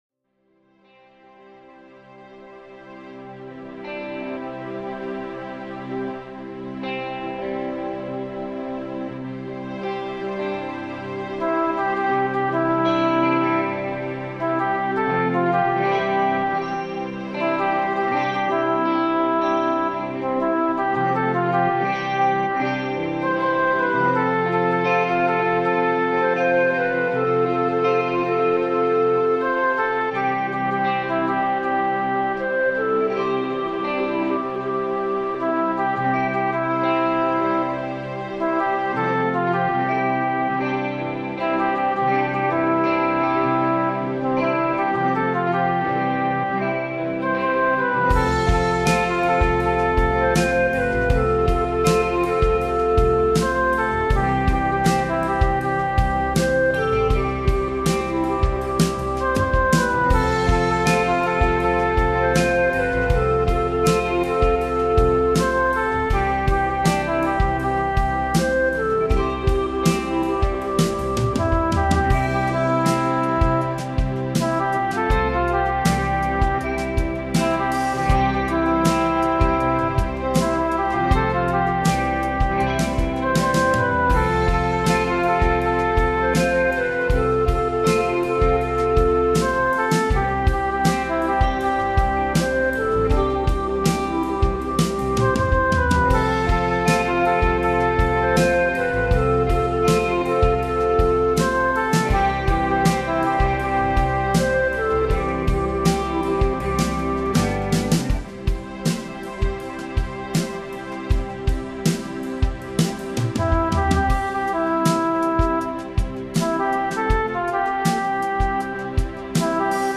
pop song
added the echo to the verse as well